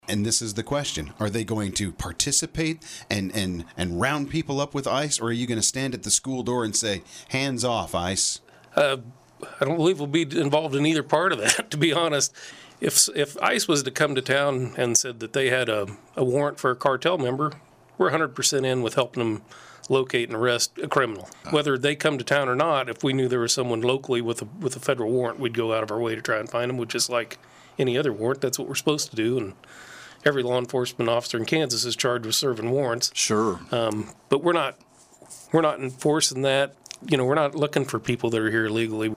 Saline County Sheriff Roger Soldan joined in on the KSAL Morning News Extra with a look at how local law enforcement works with U.S. Immigration and Customs Enforcement (ICE).
Sheriff Soldan told the KSAL audience during the interview his deputies are not out looking for people who are here illegally.